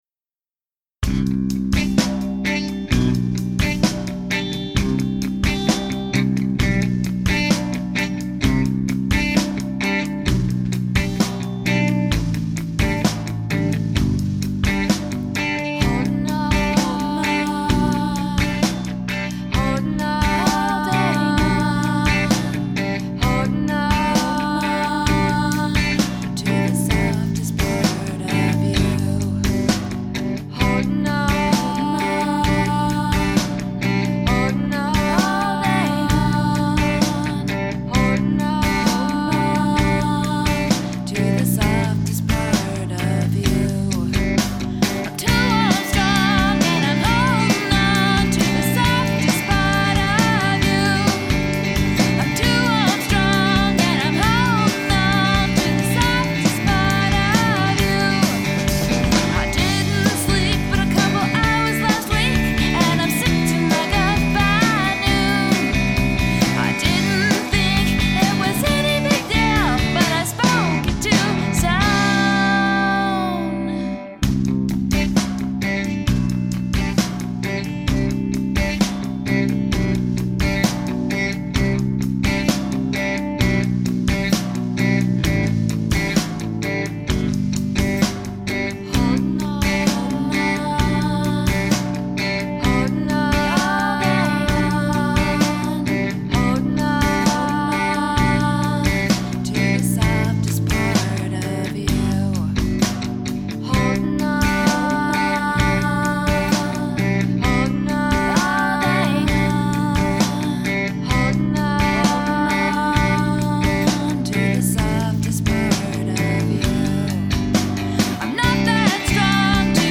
It was a 4 piece all female band, except for me.